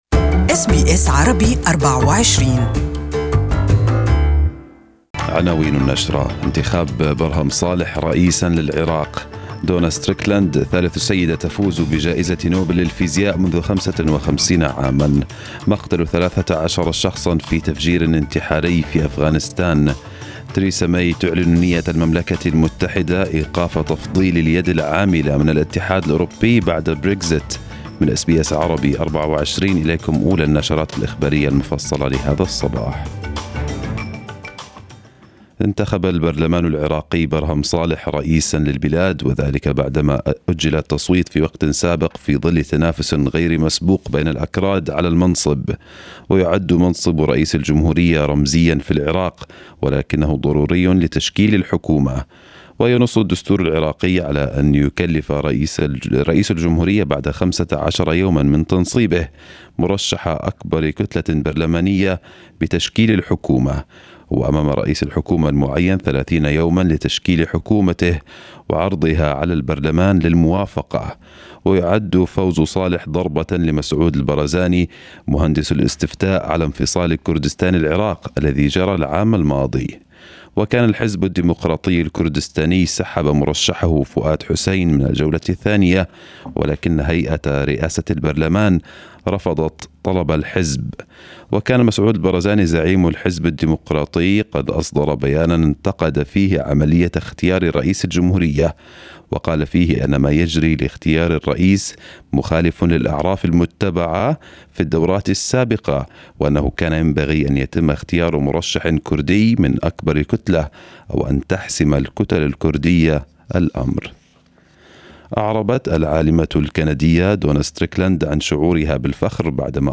News bulletin in Arabic